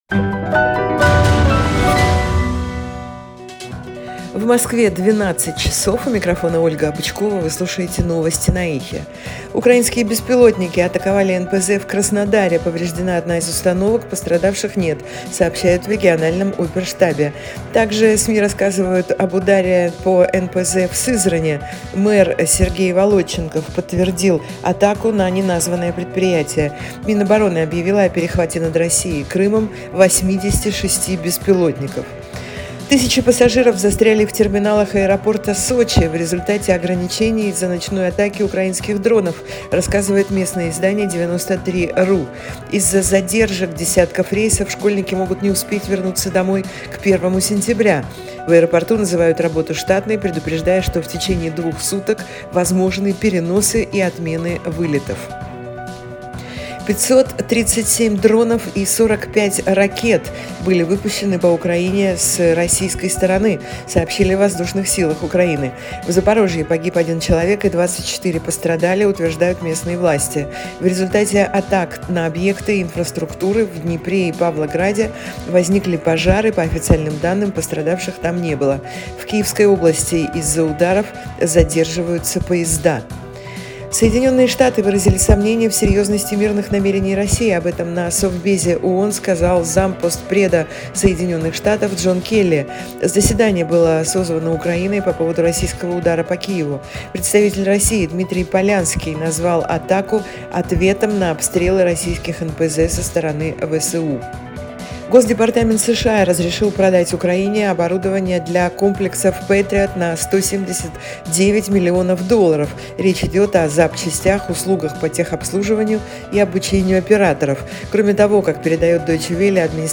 Слушайте свежий выпуск новостей «Эха»
Новости 12:00